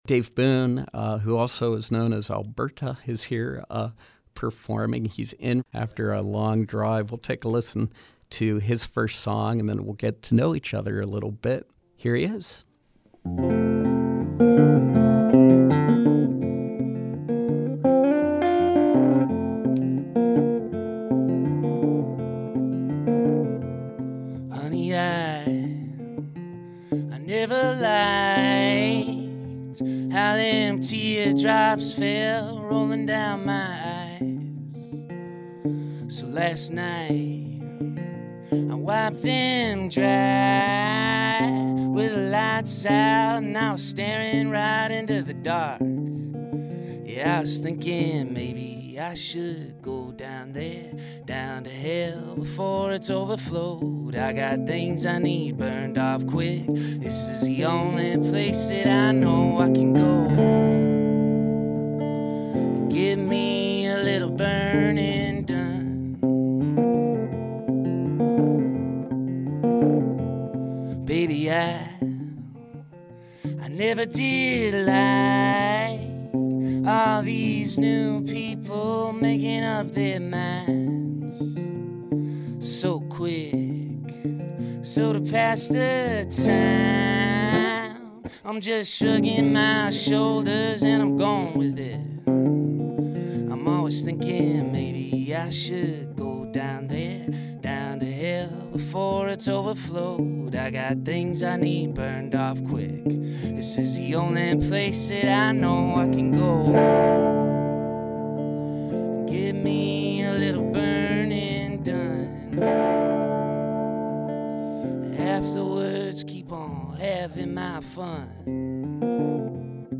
Live music with Americana-Blues artist